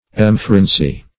emphrensy - definition of emphrensy - synonyms, pronunciation, spelling from Free Dictionary Search Result for " emphrensy" : The Collaborative International Dictionary of English v.0.48: Emphrensy \Em*phren"sy\, v. t. To madden.